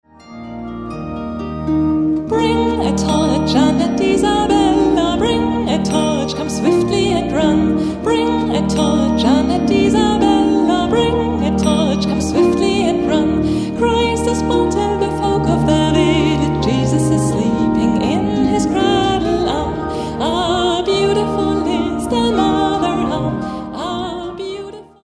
Advents- und Weihnachtsmusik